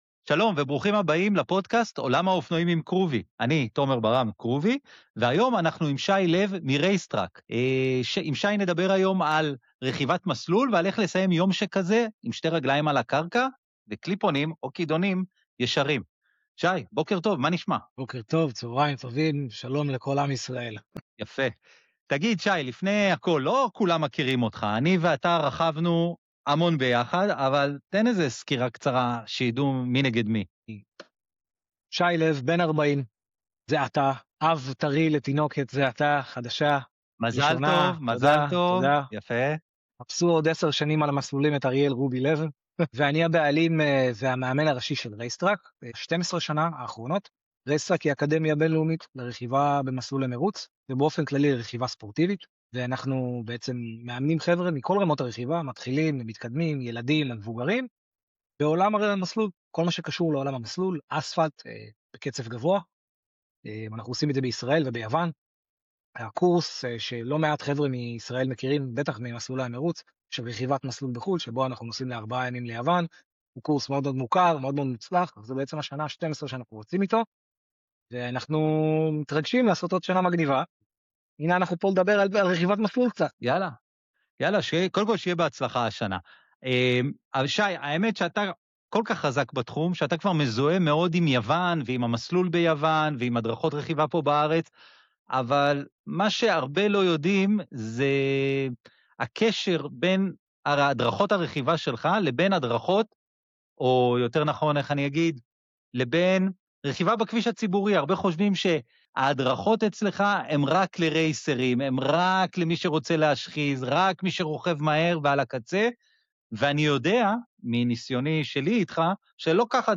שיחה על רבדים, גוונים והתפתחות אישית דרך הרכיבה.